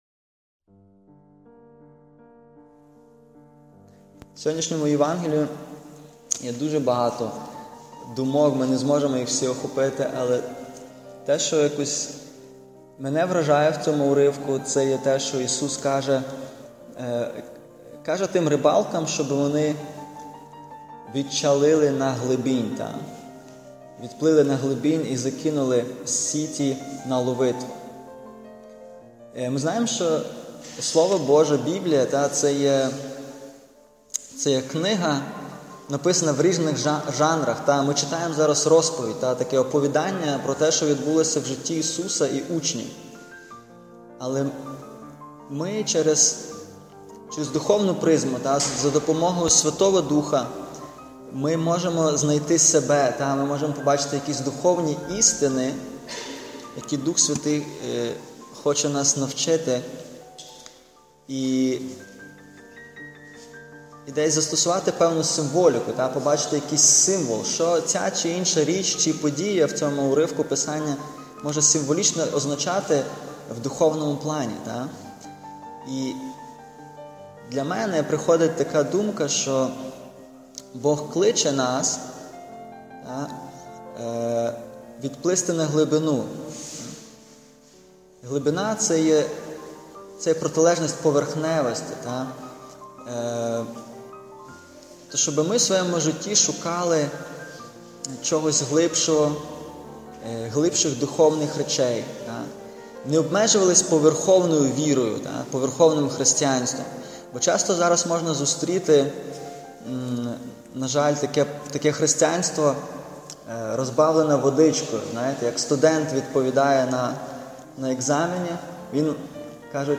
Проповіді